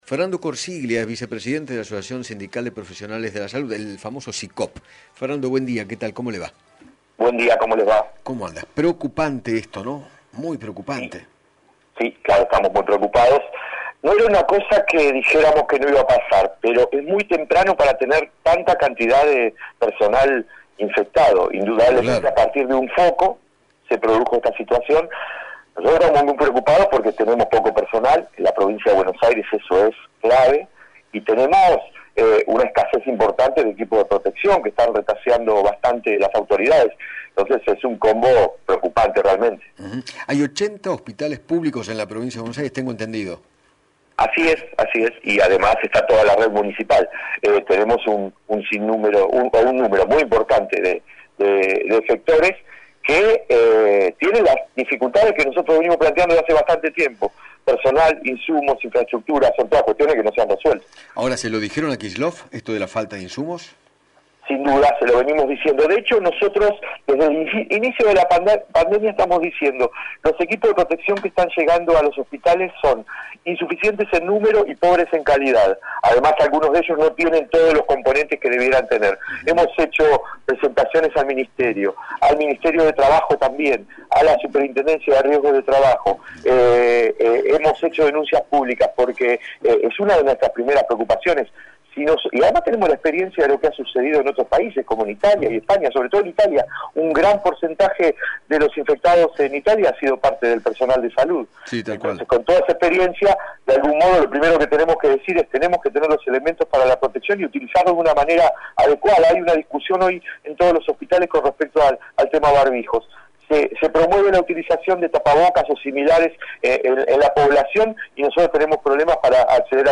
dialogó con Eduardo Feinmann sobre la noticia de los 15 profesionales de la salud contagiados de Coronavirus pertenecientes al Hospital Gral.